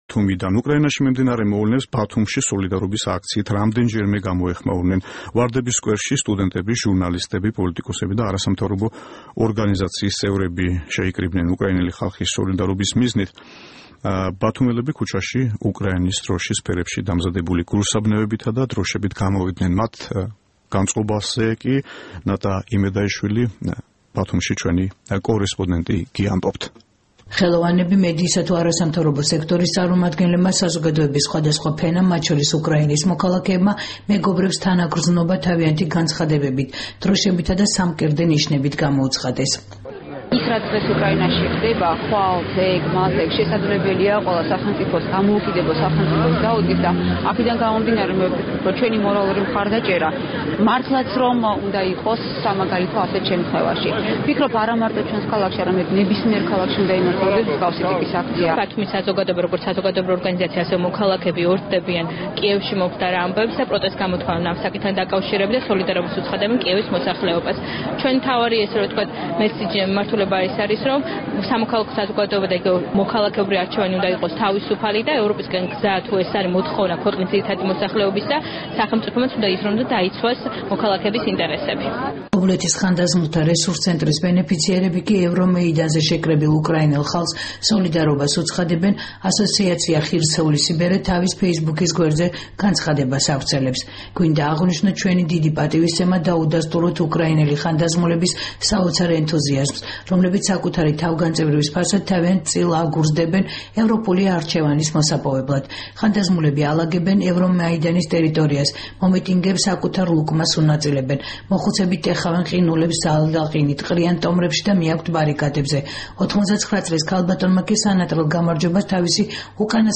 უკრაინელი ხალხის მხარდამჭერი აქცია ბათუმში